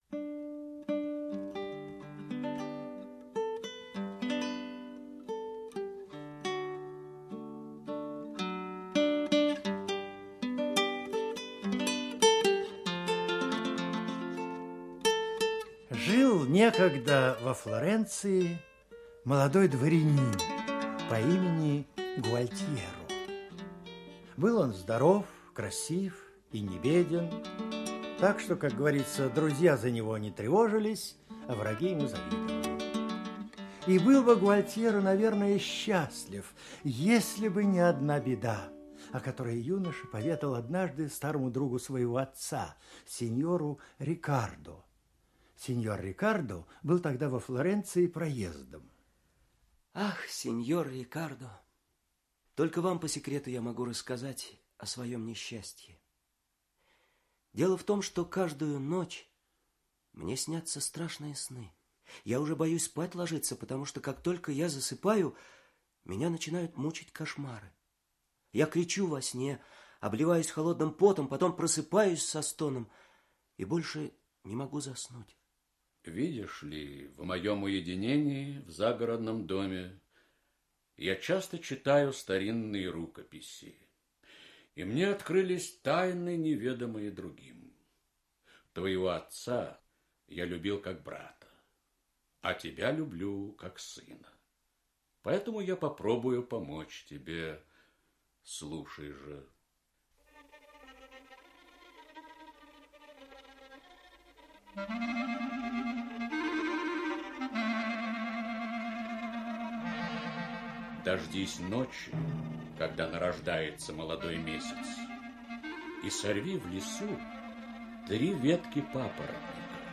Сны Гуалтьеро - итальянская аудиосказка - слушать онлайн
Инсценировка